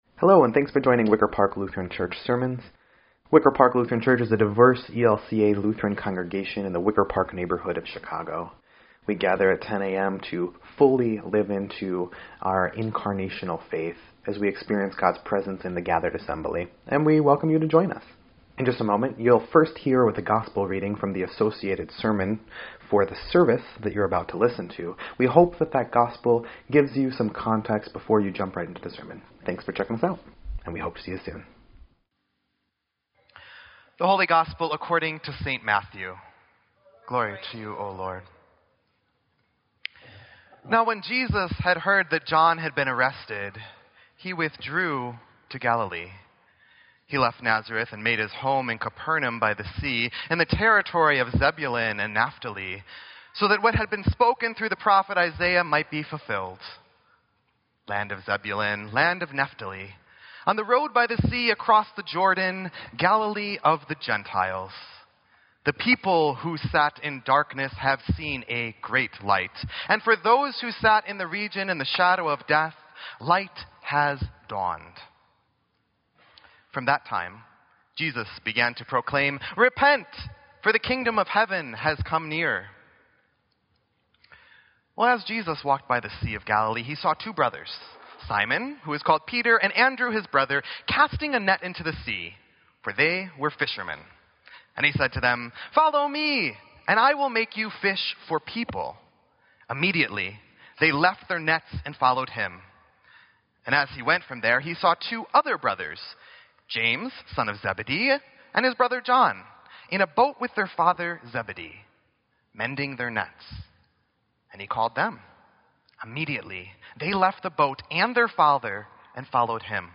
Sermon_1_22_17.mp3